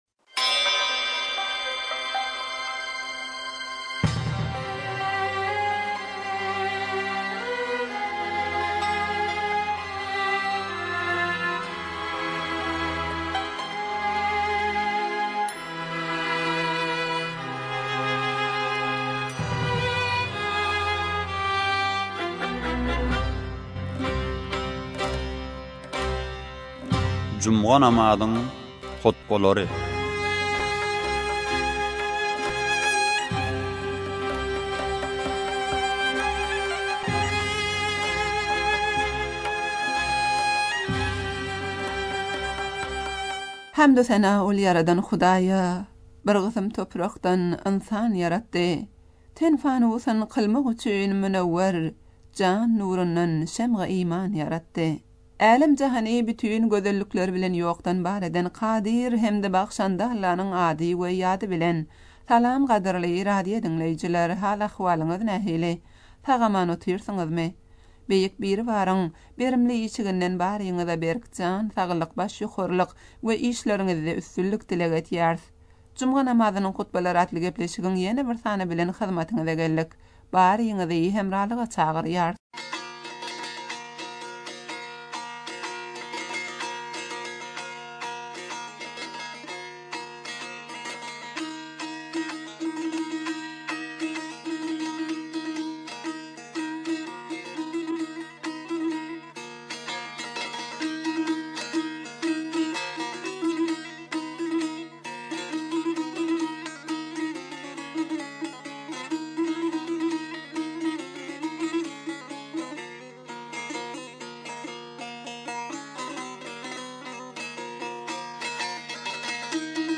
Juma namzynyň hotbalary